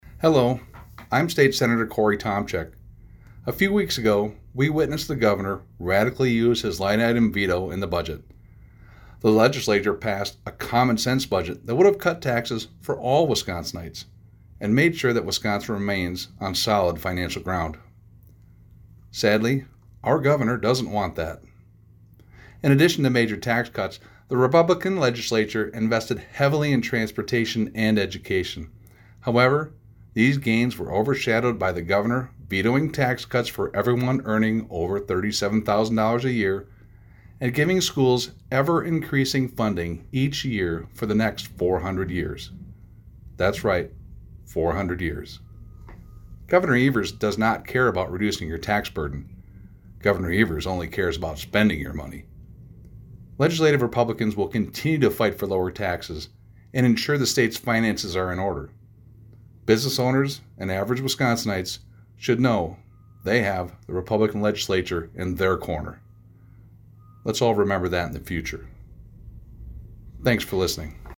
Weekly GOP radio address: Republicans continue fighting for lower taxes - WisPolitics